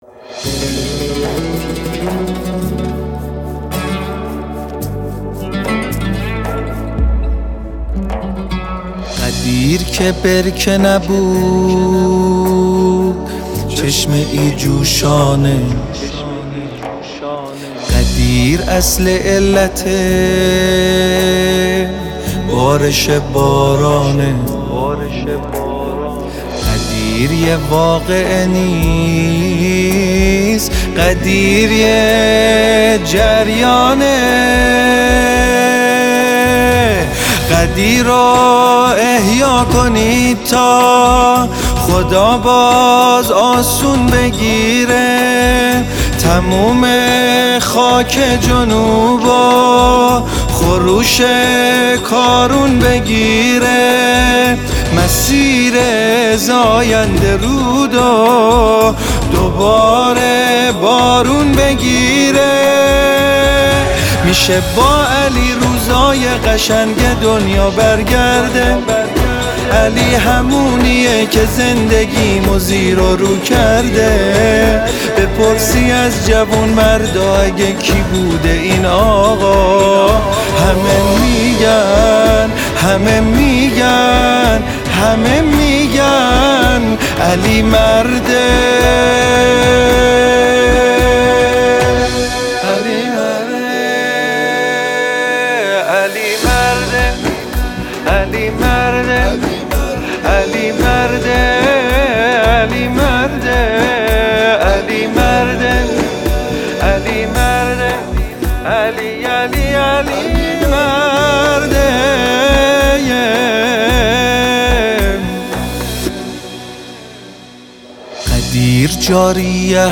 زیبا و دلنشین